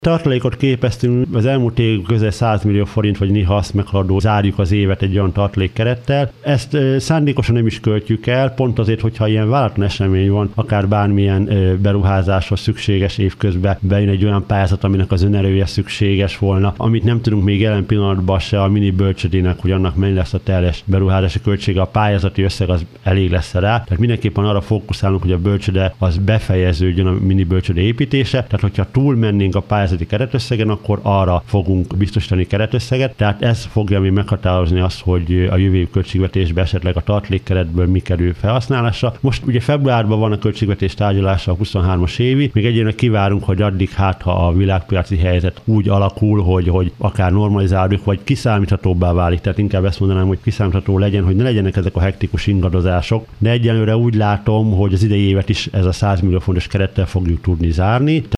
Hírek
Pulisch József polgármester arról beszélt, talán sikerül a jövő évre is megtartani ezt a keretet.